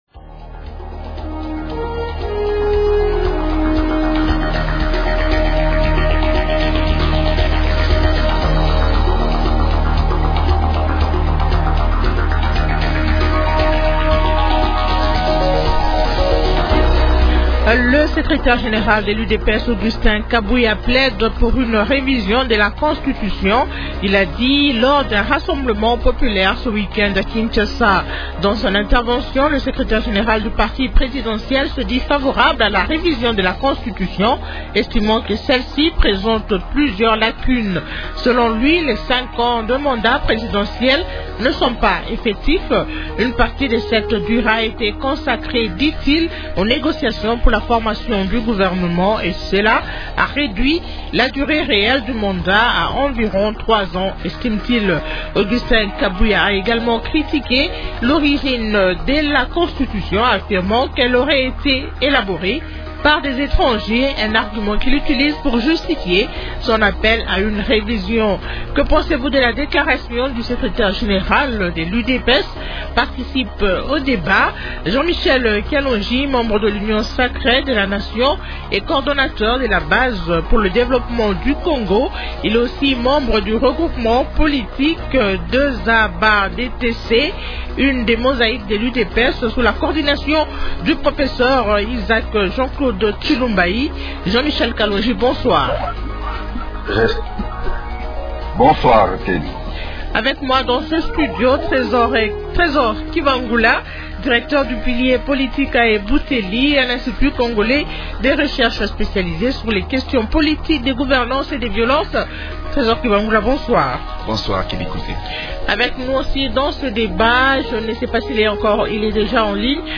Question : -Que pensez-vous de la déclaration du secrétaire général de l’UDPS ?